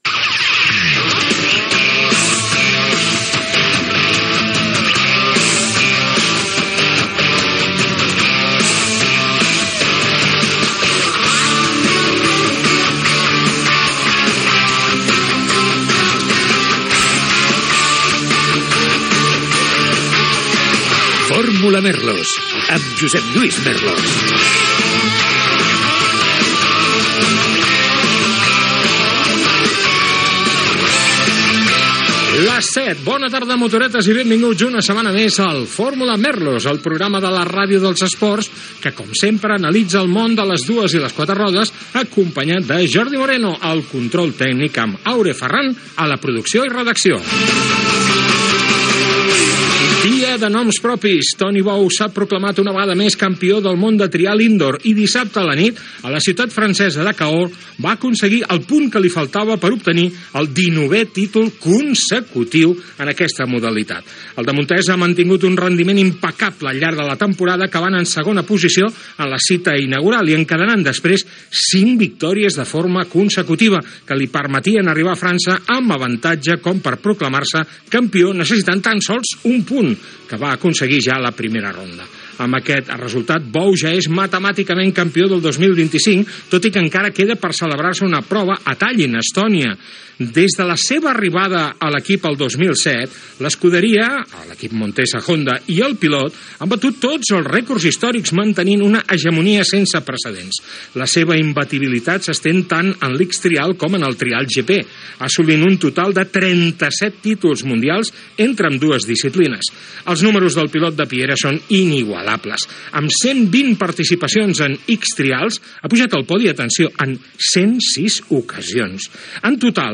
Indicatiu de la ràdio, careta, hora, equip, Antoni Bou campió del món de trial, victòria d'Àlex Palou a la cursa de l'Indy Car (EE.UU.), el saló Automobile Barcelona, indicatiu, publicitat
Esportiu